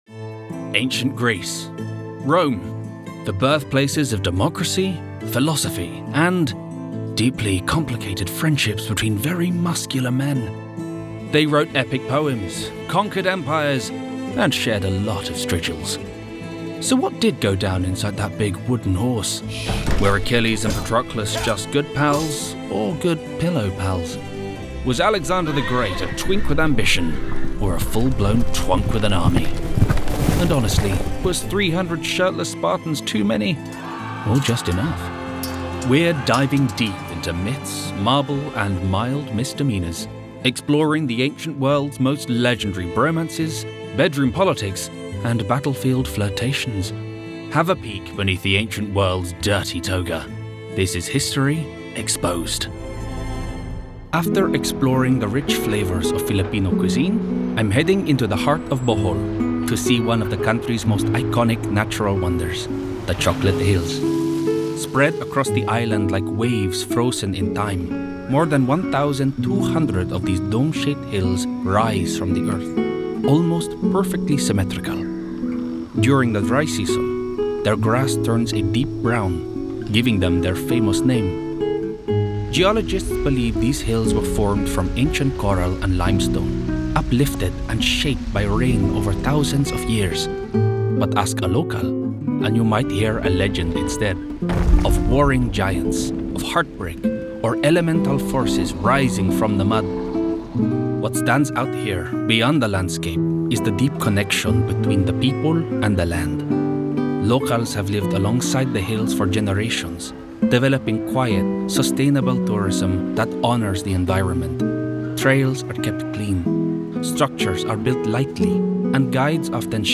Documentary Showreel
Male
Neutral British
British RP
Cool
Youthful
Confident
Friendly